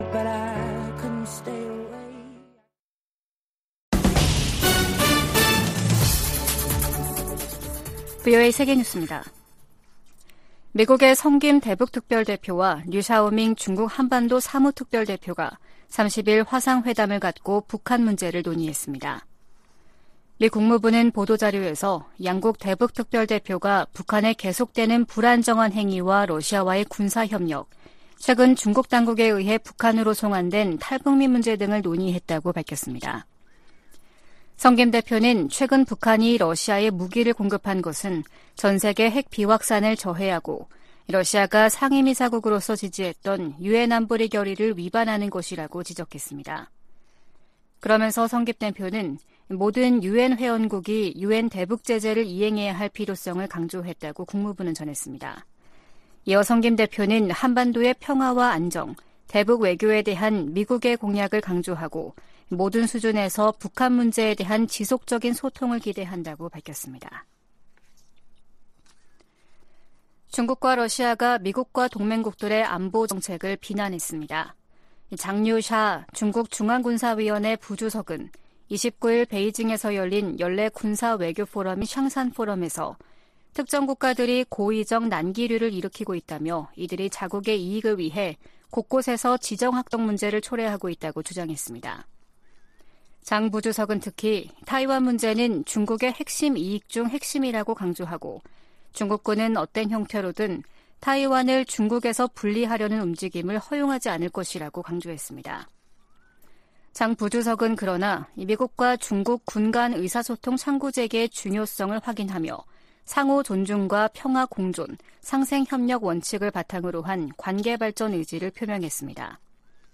VOA 한국어 아침 뉴스 프로그램 '워싱턴 뉴스 광장' 2023년 10월 31일 방송입니다. 유엔총회 제1위원회가 북한의 핵무기와 대량살상무기 폐기를 촉구하는 내용이 포함된 결의안 30호를 통과시키고 본회의에 상정했습니다. 하마스가 이스라엘 공격에 북한제 대전차무기를 사용하고 있다고 중동문제 전문가가 말했습니다. 미 국무부는 북한과의 무기 거래를 부인한 러시아의 주장을 일축하고, 거래 사실을 계속 폭로할 것이라고 강조했습니다.